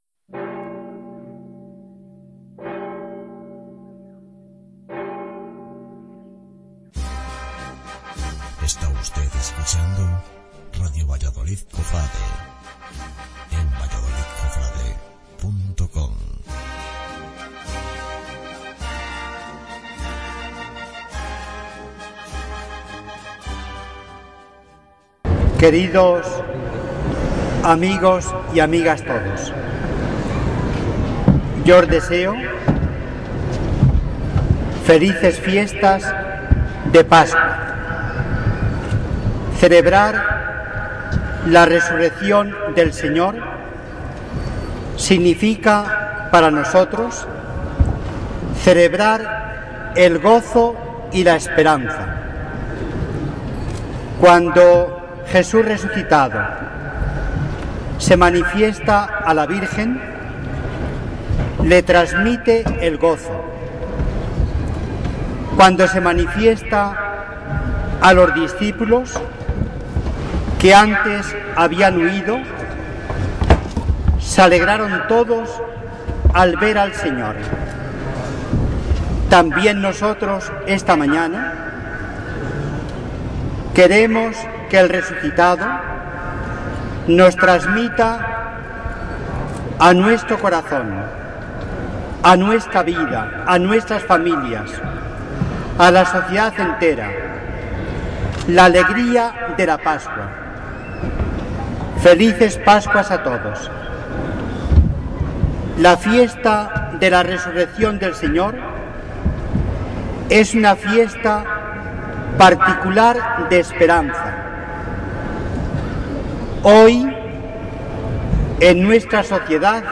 Palabras del Arzobispo, D. Ricardo Blazquez